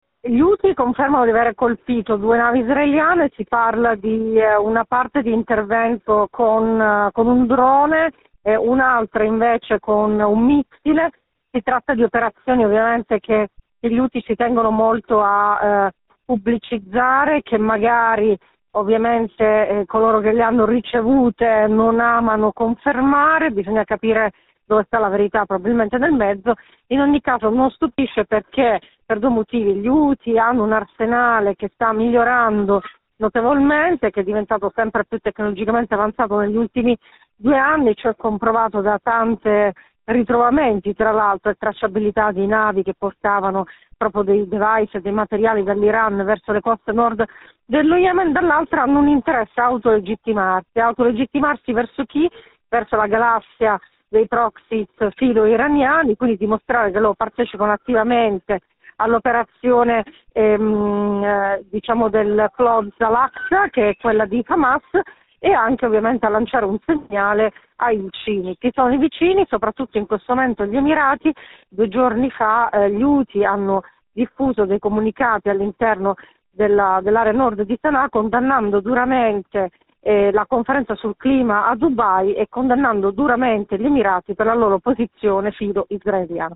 giornalista esperta di Yemen